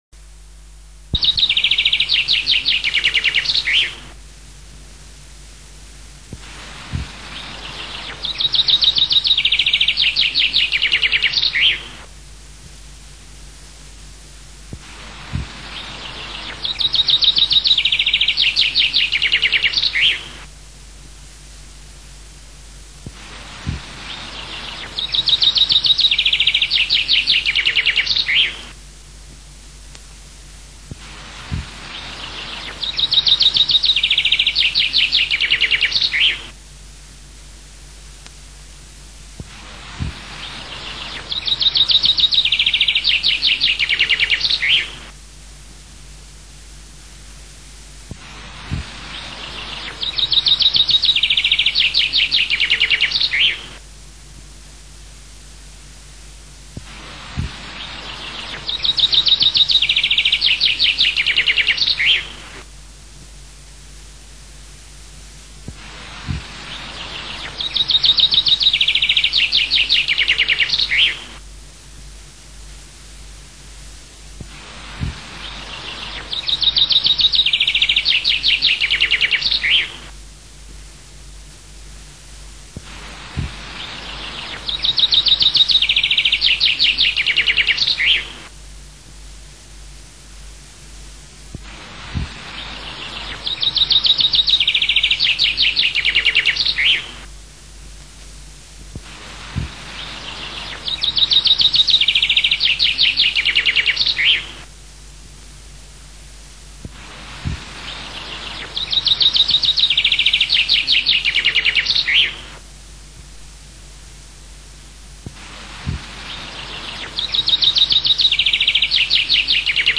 Canto del fringuello
1FRINGUELLO.wma